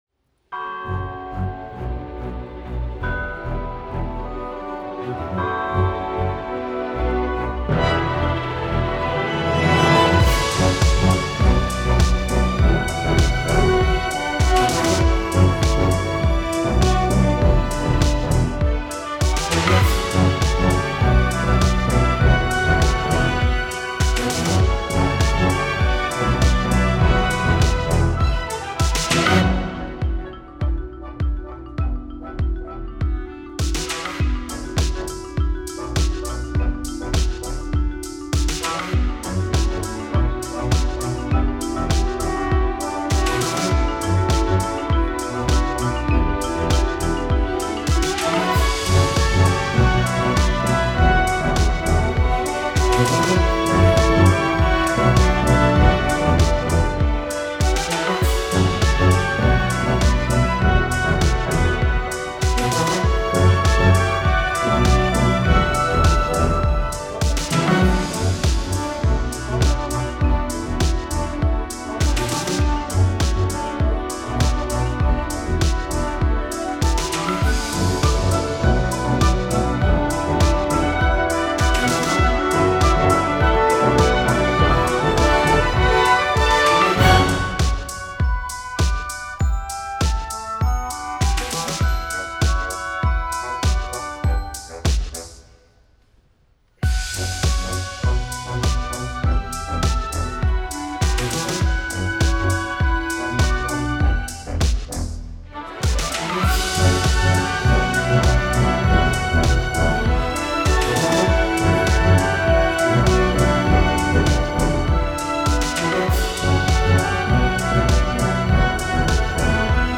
Version instrumentale